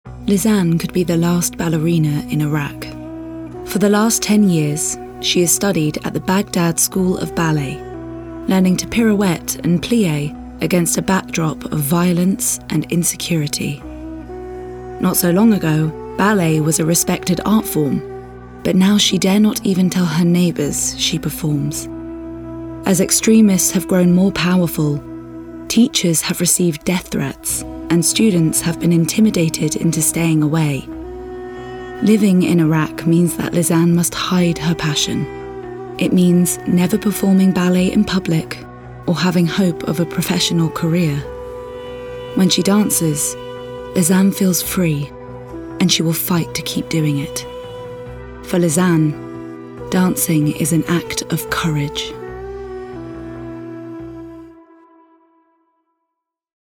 Playing age: Teens - 20s, 20 - 30sNative Accent: RPOther Accents: American, Australian, Liverpool, London, Manchester, Neutral, Northern, RP, West Country
• Native Accent: British RP